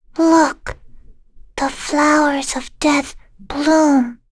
Jane-Vox_Skill3.wav